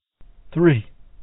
man3.mp3